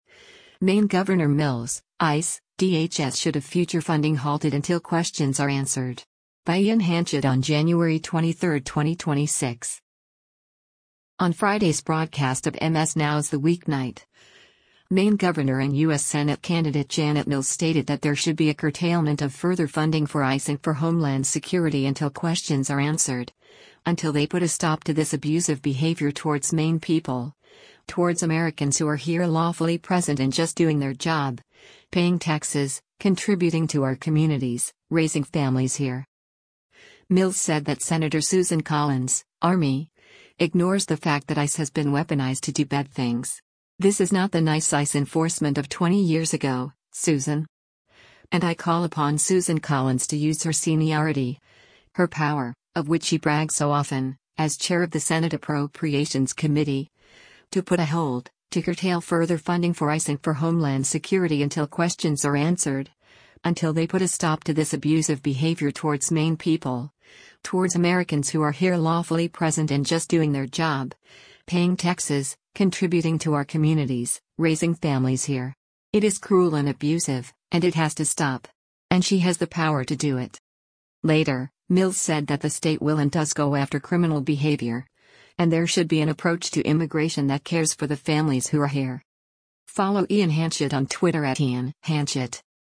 On Friday’s broadcast of MS NOW’s “The Weeknight,” Maine Gov. and U.S. Senate candidate Janet Mills (D) stated that there should be a curtailment of “further funding for ICE and for Homeland Security until questions are answered, until they put a stop to this abusive behavior towards Maine people, towards Americans who are here lawfully present and just doing their job, paying taxes, contributing to our communities, raising families here.”